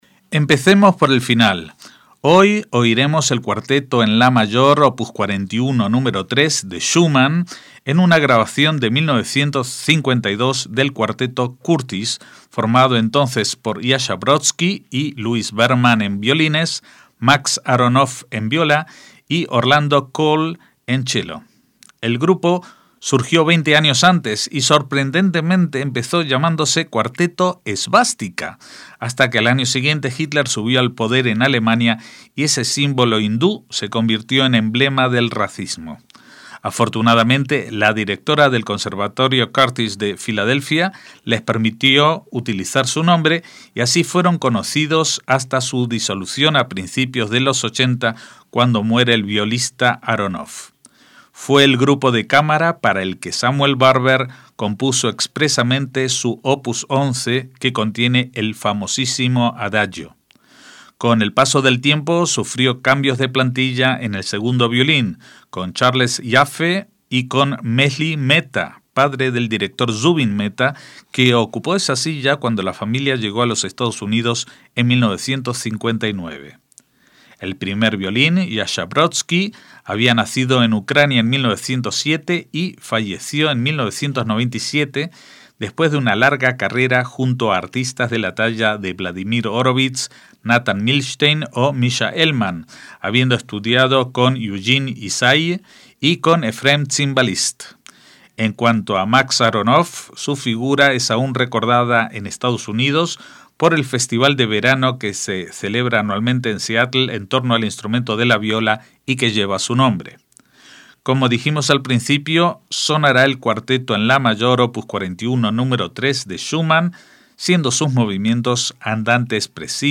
MÚSICA CLÁSICA
Jascha Brodsky como primer violín
Max Aronoff en viola
Andante espressivo – Allegro molto moderato, Assai agitato, Adagio molto y Finale